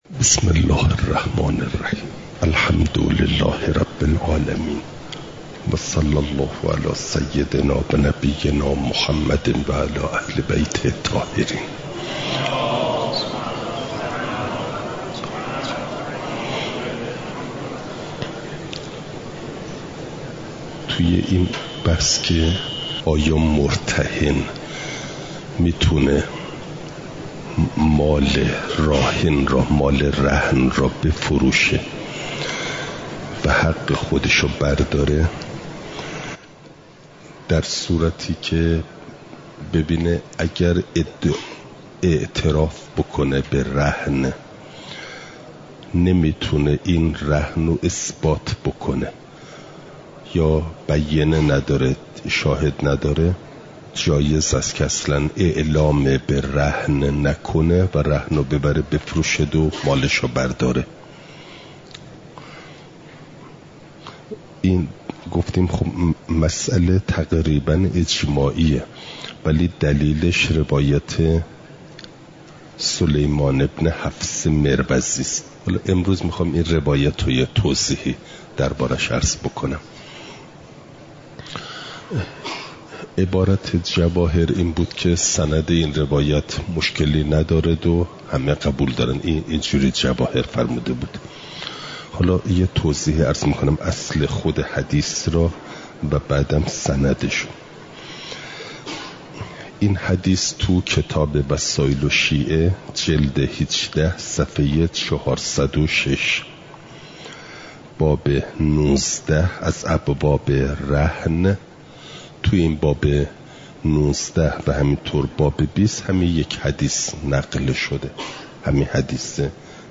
مسائل مستحدثه قضا (جلسه۸۸) – دروس استاد